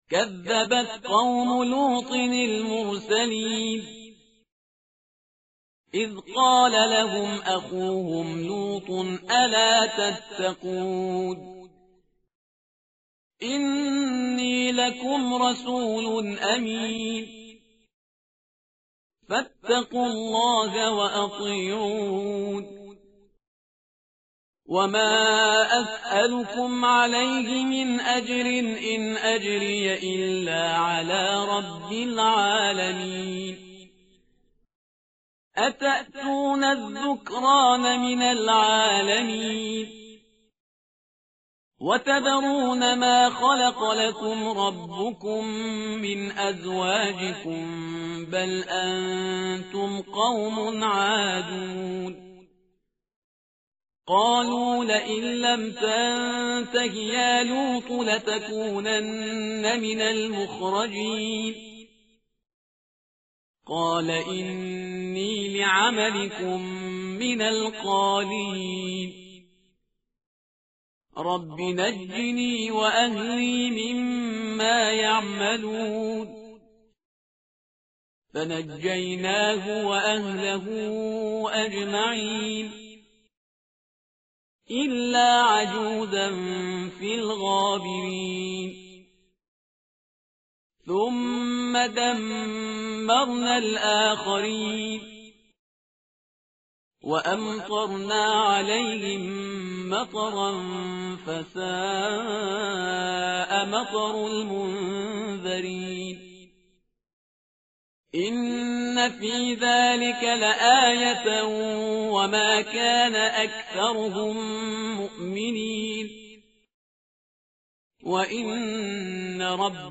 متن قرآن همراه باتلاوت قرآن و ترجمه
tartil_parhizgar_page_374.mp3